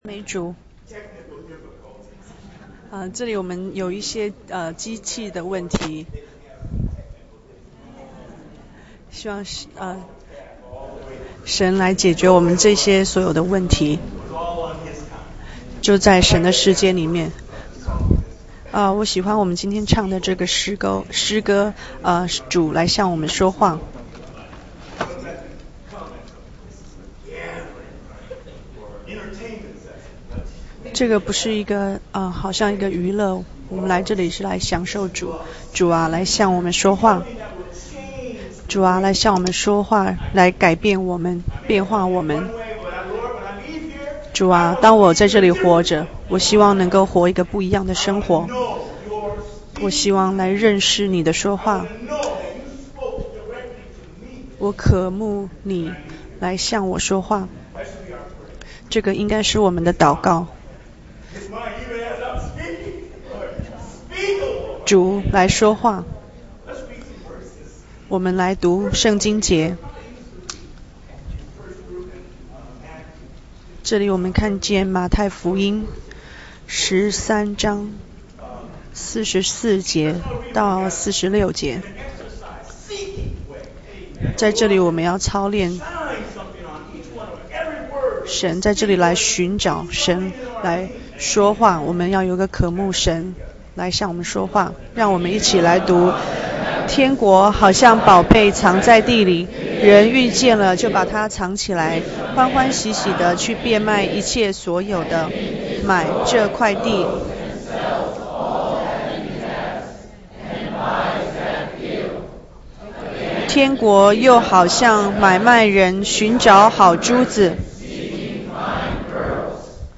2010.6.6 主日–Jubilee